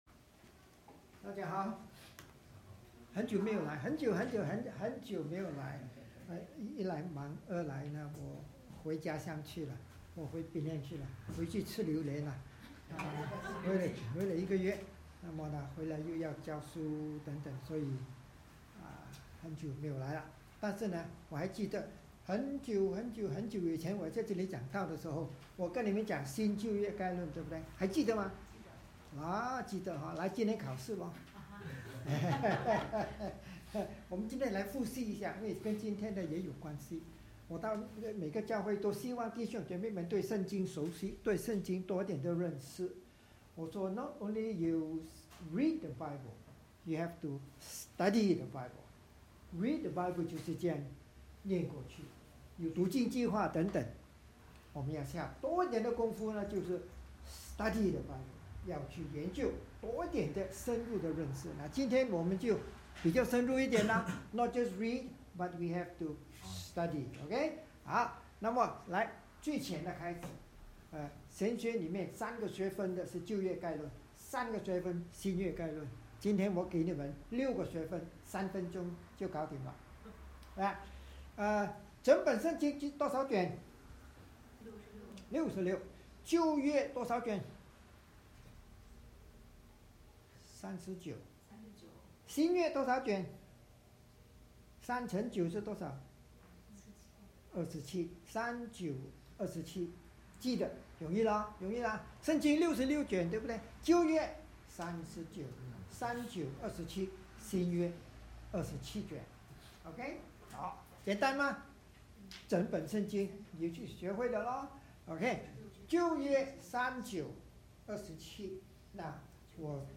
（利未记/弗5:1-5/罗12:1-2） Service Type: 主日崇拜 通过利未记概览认识旧约会幕或圣殿的五种献祭，劝勉我们要效法基督将自己献上作活祭，在教会与个人生活的每个层面不要效法这个世界，而要藉着圣灵的重生活出神所喜悦的圣洁生命。